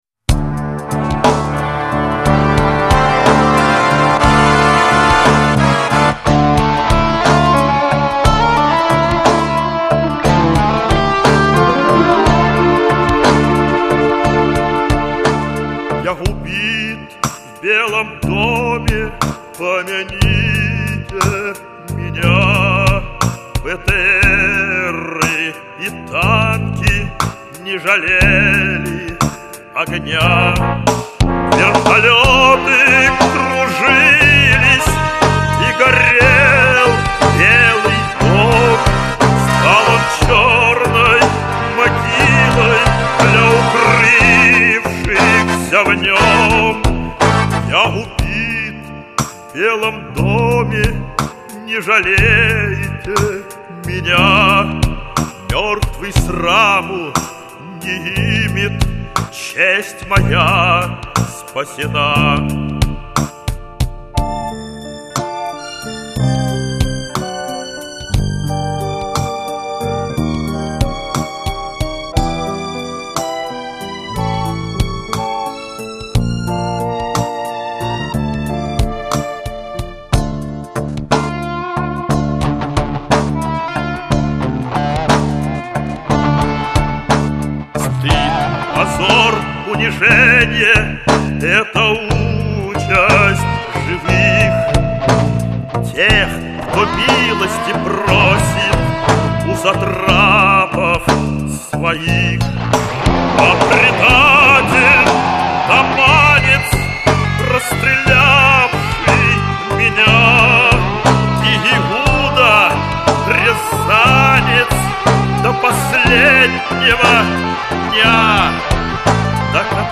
Магнитофонная кассета
Соло-гитара